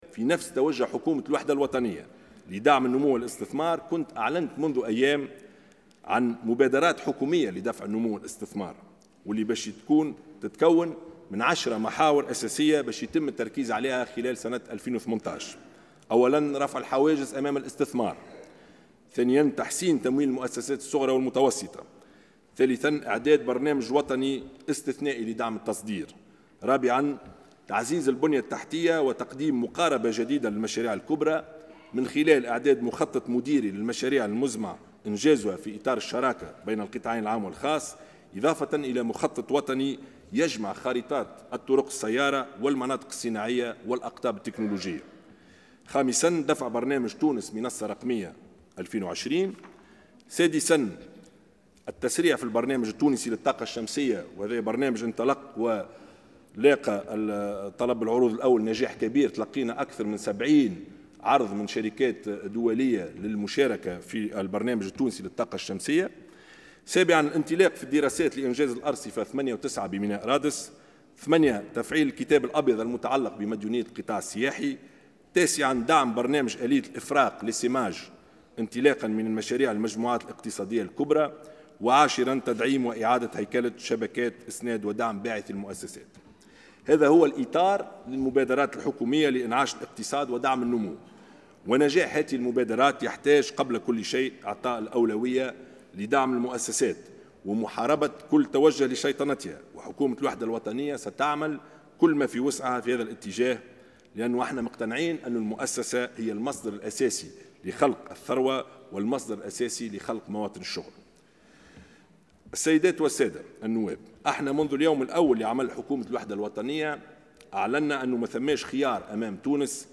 أكّد رئيس الحكومة يوسف الشاهد اليوم الثلاثاء خلال عرضه بيان الحكومة والتقرير العام لميزانية الدولة لسنة 2018، في جلسة عامة بالبرلمان، عزم حكومته مواصلة الإصلاحات رغم الصعوبات التي تعترضها غالبا.